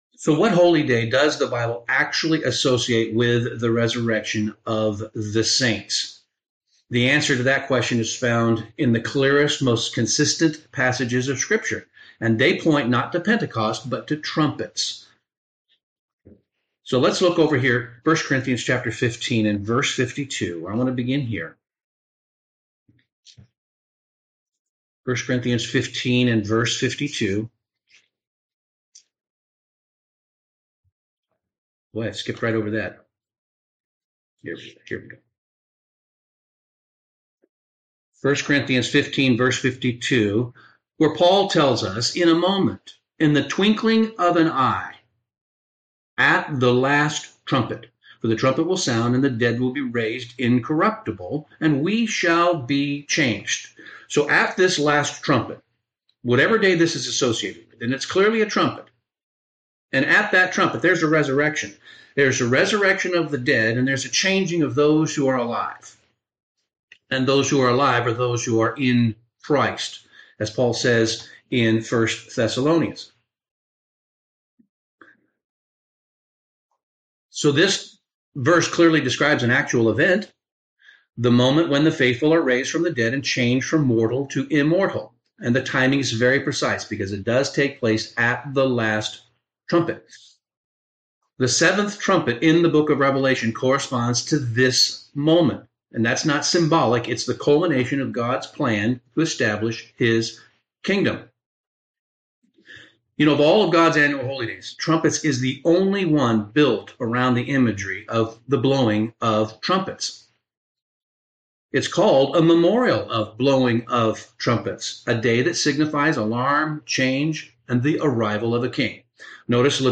Bible Study - The Resurrection is Not Pictured by Pentecost